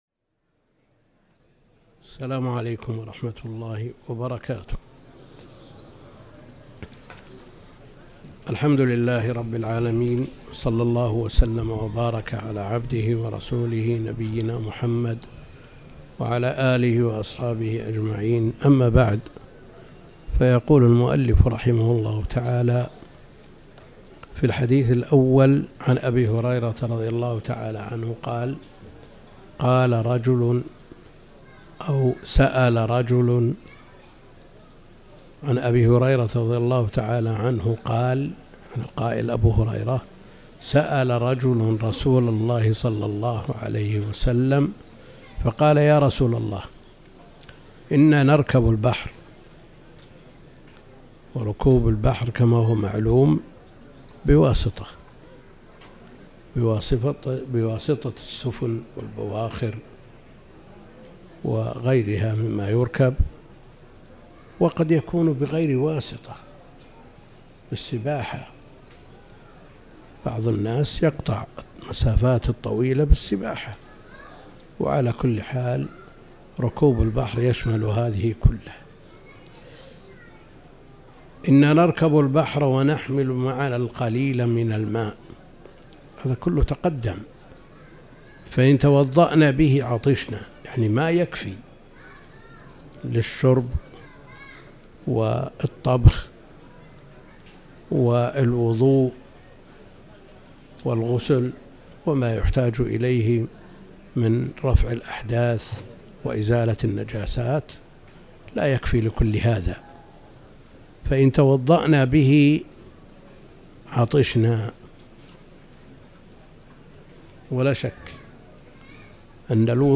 تاريخ النشر ٢ ذو القعدة ١٤٤٠ هـ المكان: المسجد الحرام الشيخ: فضيلة الشيخ د. عبد الكريم بن عبد الله الخضير فضيلة الشيخ د. عبد الكريم بن عبد الله الخضير الماء حال السفر بالبحر